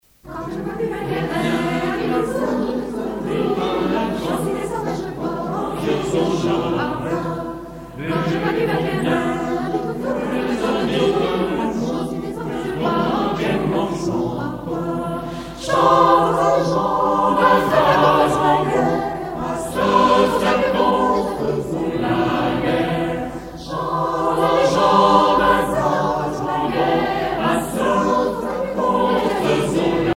danse : tourdion (renaissance)
Genre strophique
Pièce musicale éditée